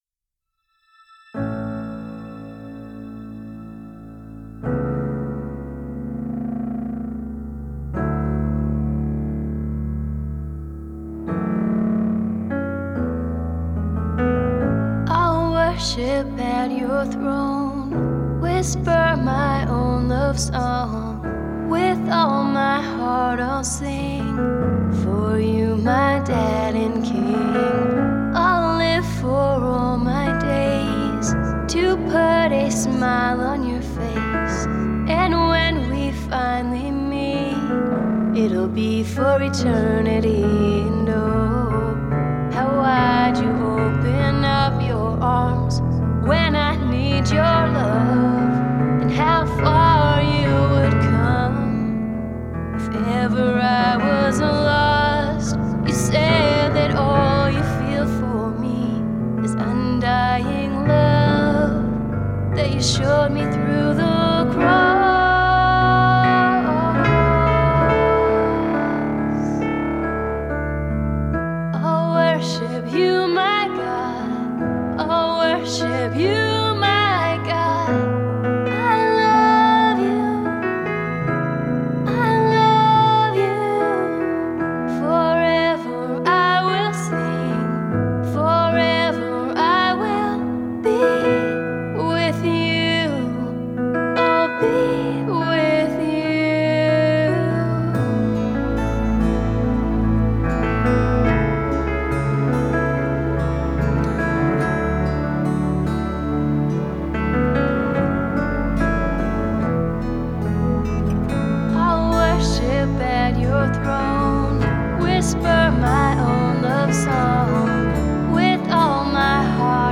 Vocals
Keys
Guitar